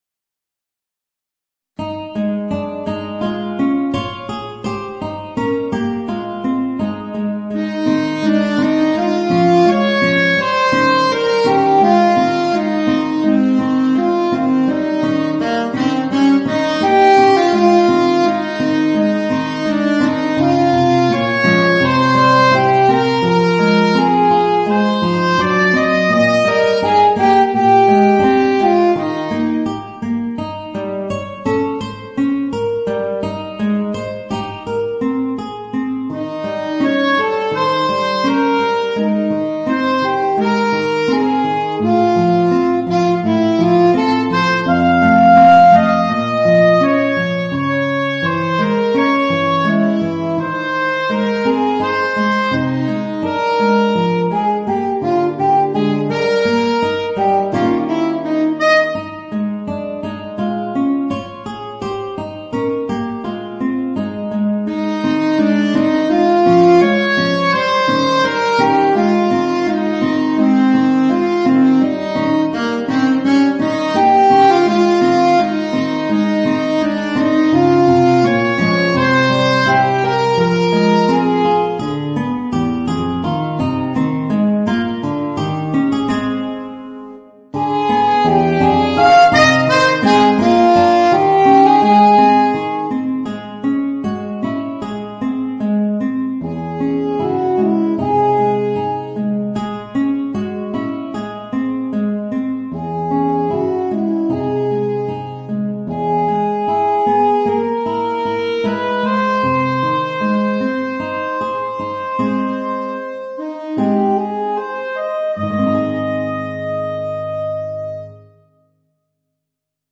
Voicing: Alto Saxophone and Guitar